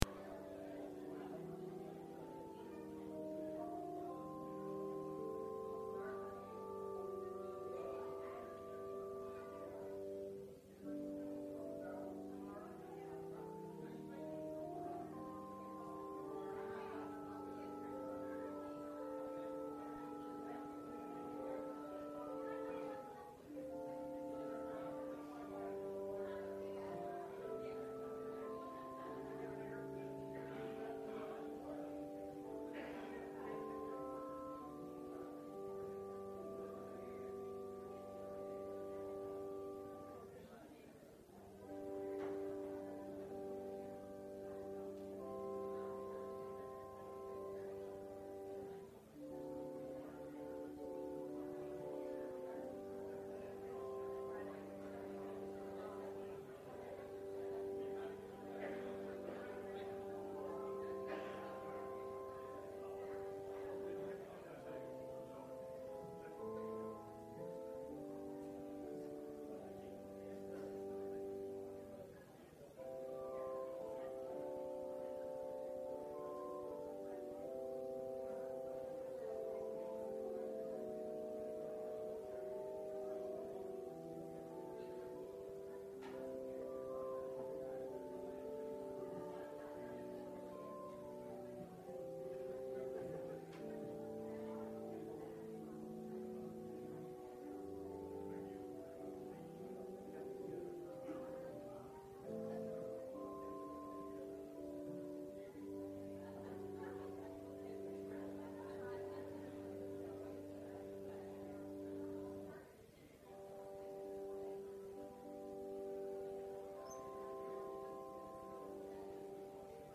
Several members shared their stories about the "Under the Bridge" ministry.
35 Service Type: Sunday Worship Several members shared their stories about the "Under the Bridge" ministry.